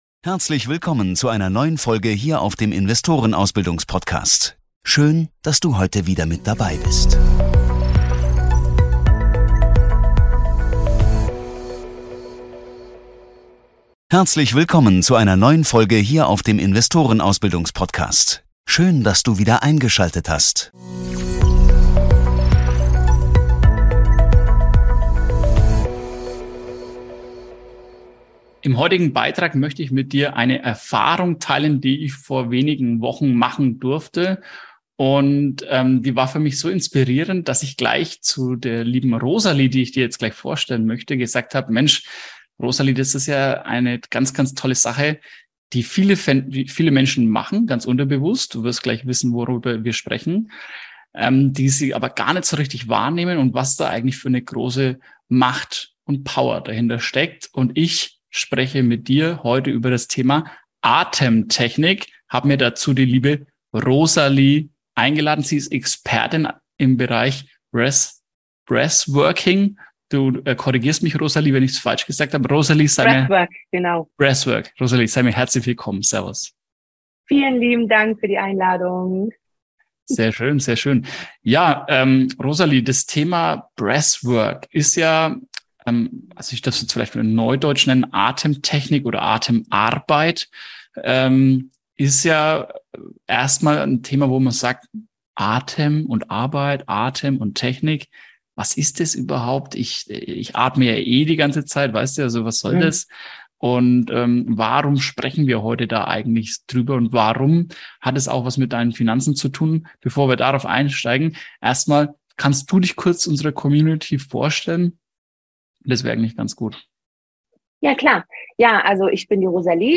Dieses Interview besteht aus 2 Teilen, der zweite Teil kommt nächsten Dienstag, den 11.10.2022. Es geht um Breathwork, also Atemtechniken, mit denen ihr erfolgreicher und gesünder durch den Alltag kommt und in schwierigen Situationen die Kontrolle behaltet.